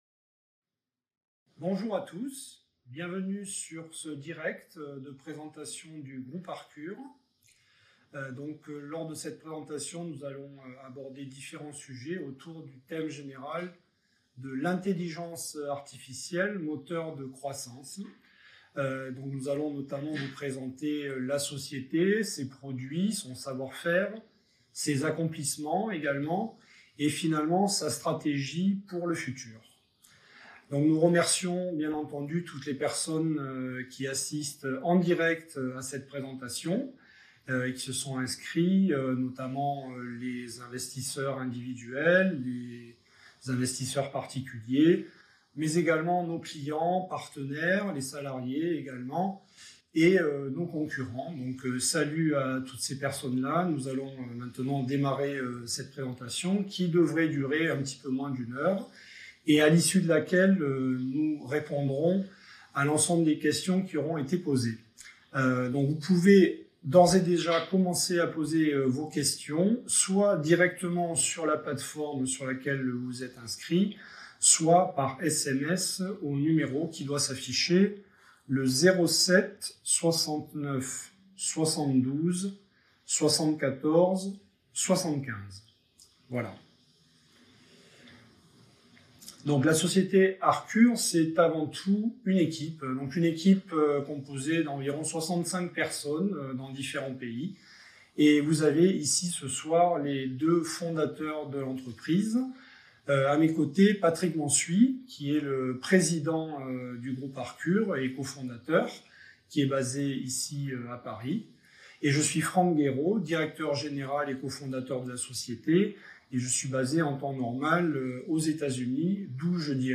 Présentation investisseurs Arcure - La Bourse et la Vie TV L'info éco à valeur ajoutée